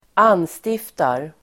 Uttal: [²'an:stif:tar]